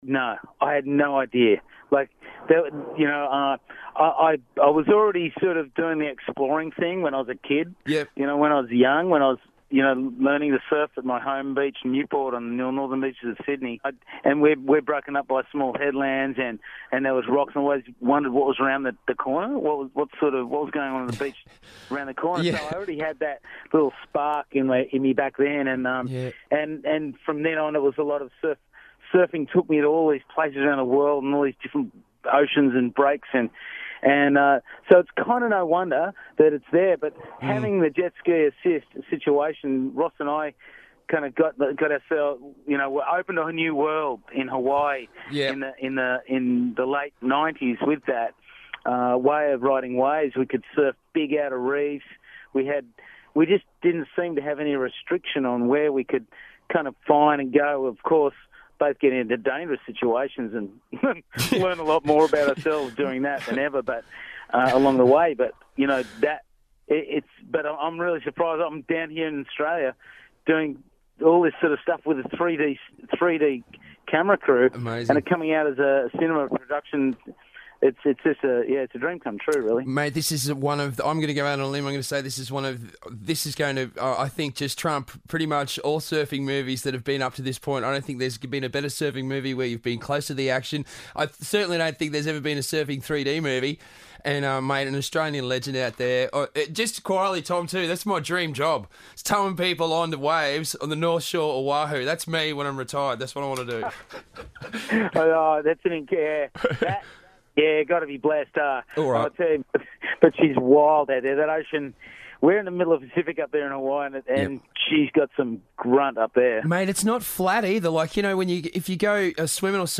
Tom Carroll - Storm Surfers 3D interview part Two